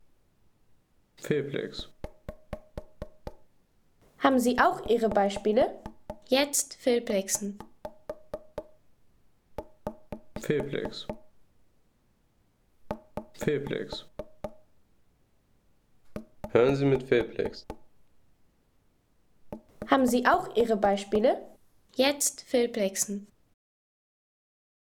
Klopfen auf Wassermelone
Klopfen auf Wassermelone Home Sounds Natur Pflanzen Klopfen auf Wassermelone Seien Sie der Erste, der dieses Produkt bewertet Artikelnummer: 24 Kategorien: Natur - Pflanzen Klopfen auf Wassermelone Lade Sound....